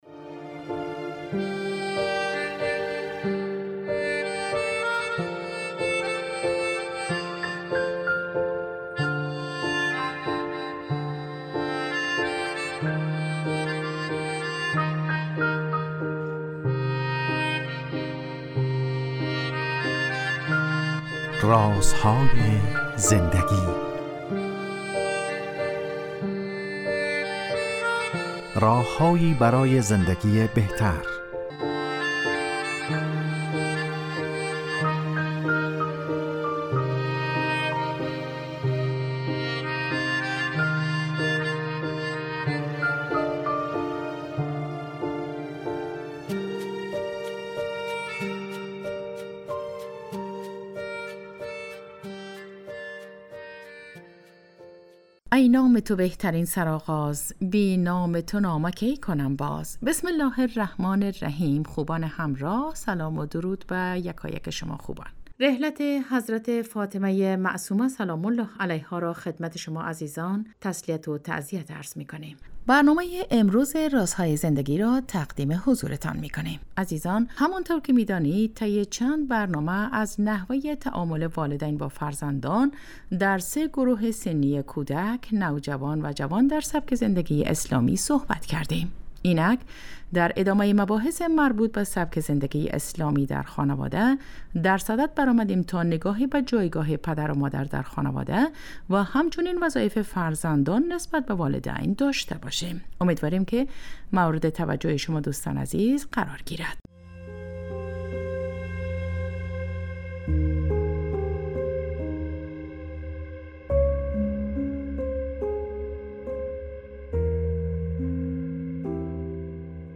با مجموعه برنامه " رازهای زندگی" و در چارچوب نگاهی دینی به سبک زندگی با شما هستیم. این برنامه به مدت 15 دقیقه هر روز ساعت 11:35 به وقت افغانستان از رادیو دری پخش می شود .